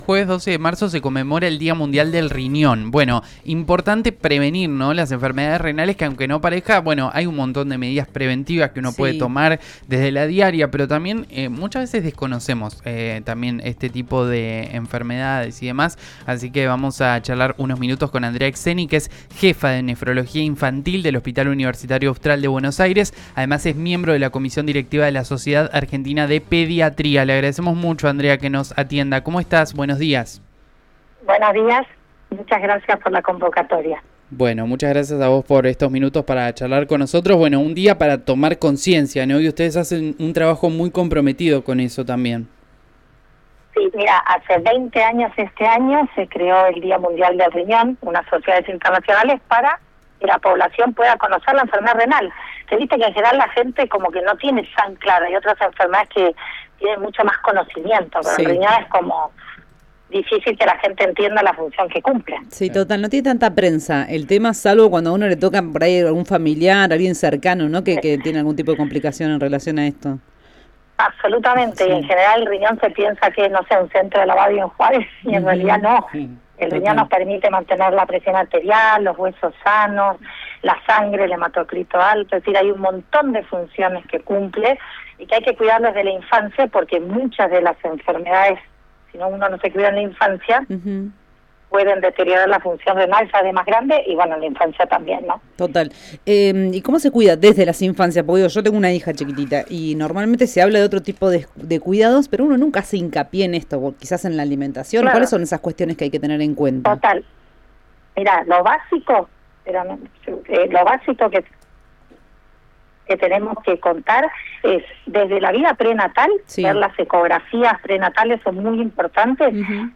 dialogó con «El diario del Mediodía» (lunes a viernes de 11 a 14) de RÍO NEGRO RADIO